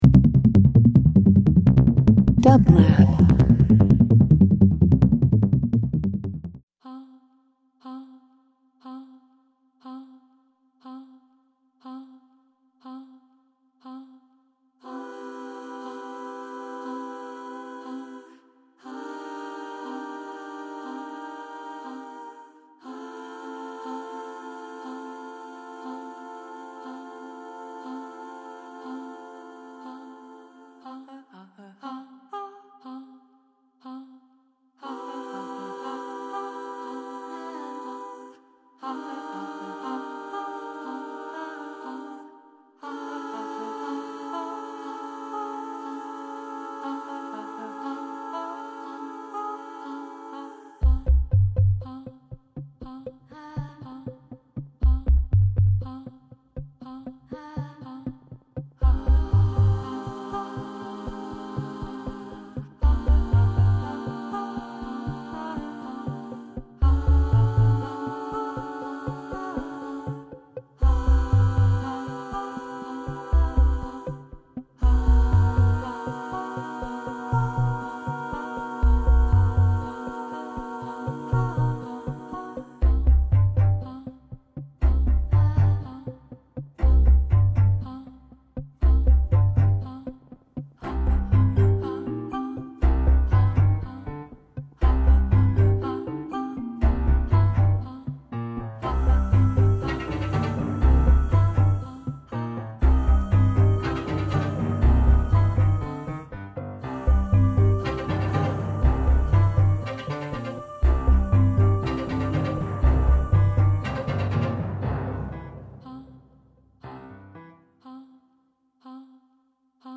Studio Soundtracks takes listeners behind the scenes of how music is crafted for film and television by hearing directly from composers, songwriters and music professionals in the Entertainment Industry. Listen to inspiring conversations about composition and hear works from Emmy, Grammy, and Oscar-winning film scores on the show.